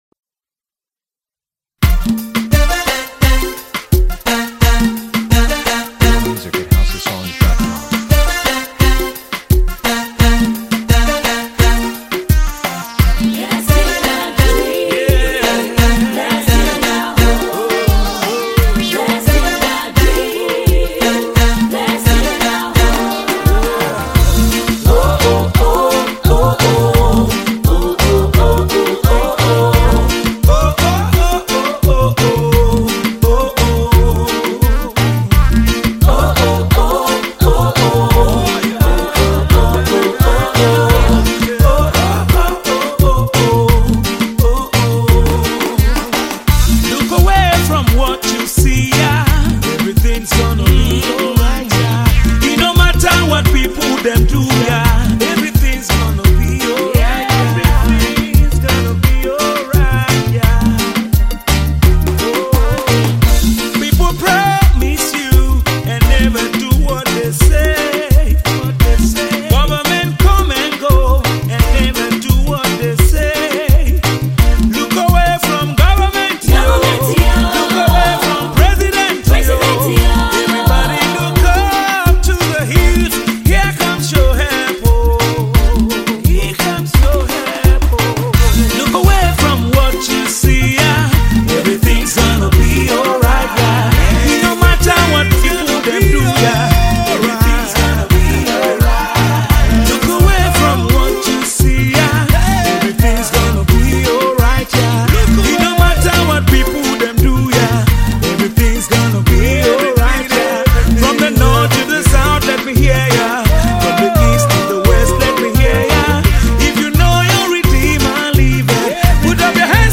encouraging, uplifts the spirit and soul
Tiv Song